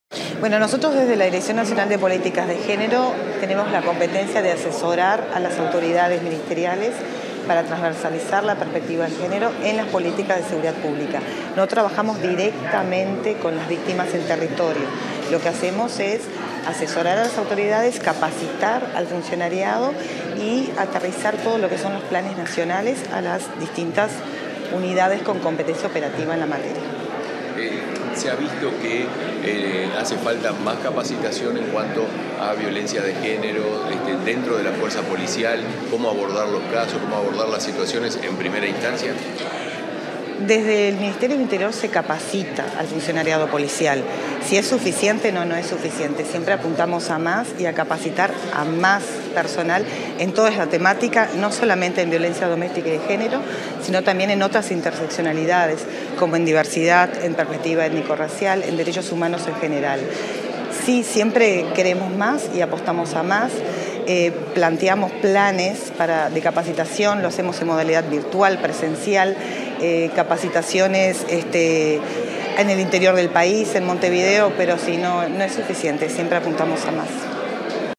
Declaraciones de la directora nacional de Políticas de Género, Angelina Ferreira
Declaraciones de la directora nacional de Políticas de Género, Angelina Ferreira 15/08/2024 Compartir Facebook X Copiar enlace WhatsApp LinkedIn Tras la inauguración del local sede y la conmemoración de un nuevo aniversario, este 15 de agosto, la directora nacional de Políticas de Género del Ministerio del Interior, Angelina Ferreira dialogó con los medios de prensa.